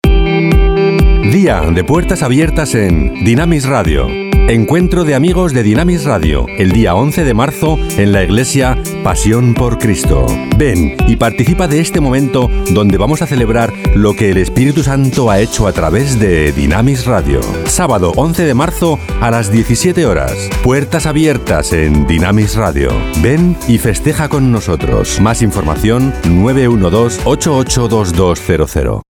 Mi voz es grave, profunda, definida, elegante y clara.
Registro barítono incluso bajo.
Sprechprobe: Werbung (Muttersprache):
My voice is deep, defined, elegant and clear.